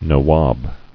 [na·wab]